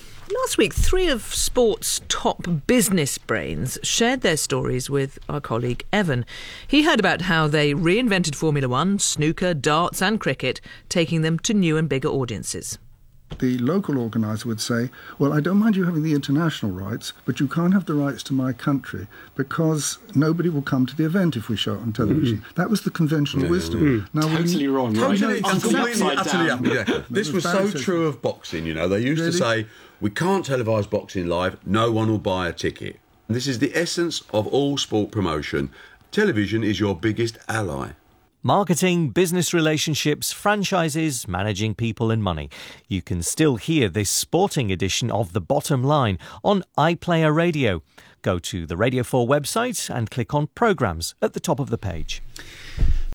short one minute trailer made me think of the arguement around the separated windows for selling DVDs for films that are released for cinema.